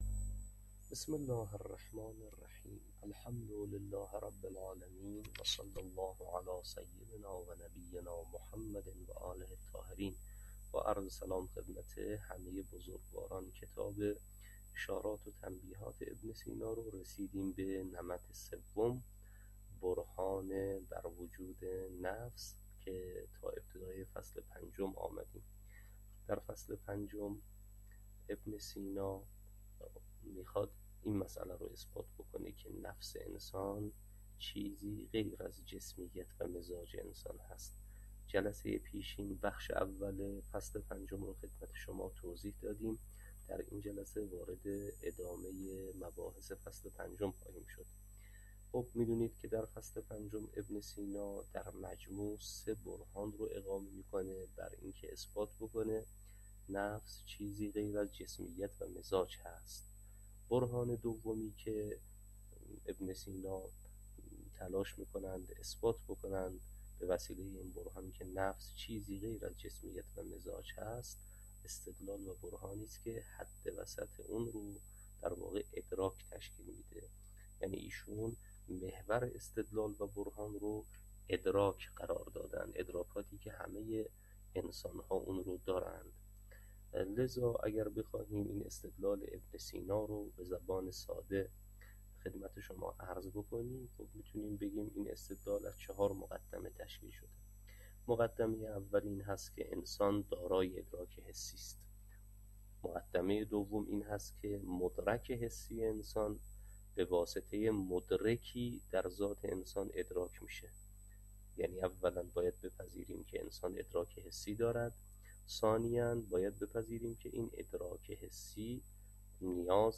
شرح اشارات و تنبیهات، تدریس